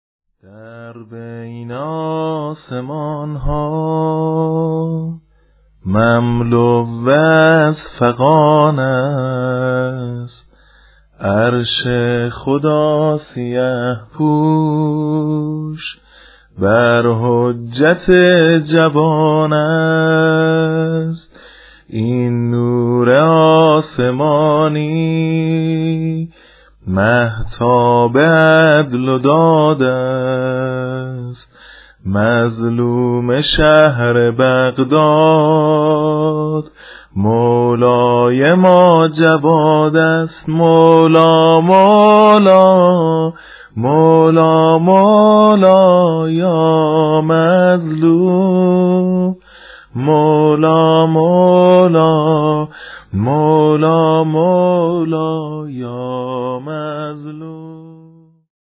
متن شعر و سبک نوحه واحد شهادت امام جواد (ع) - (در بين آسمانها مملو از فغان است)